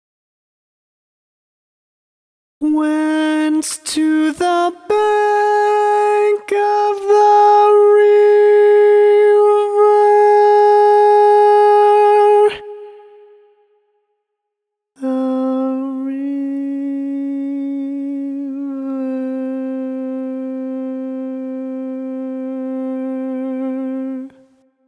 Key written in: A♭ Major
Type: Barbershop
Each recording below is single part only.